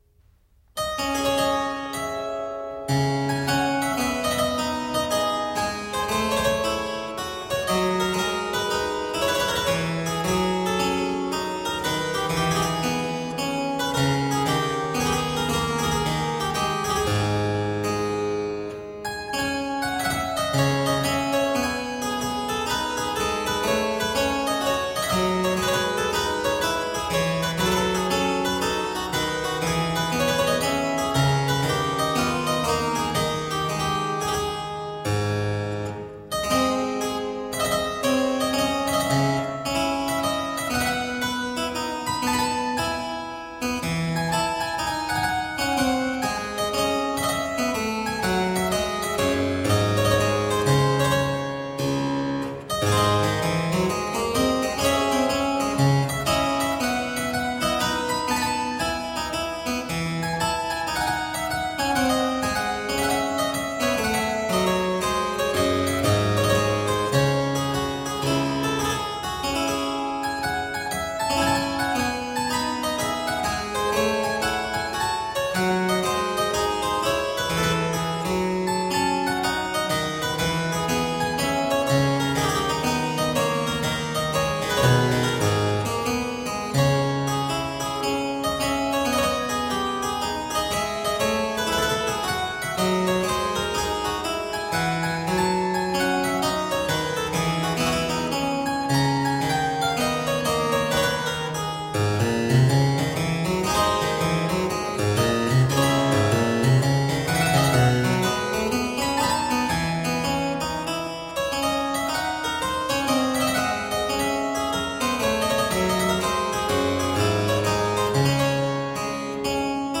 There are also harpsichord solos by Henry Purcell
Classical, Renaissance, Baroque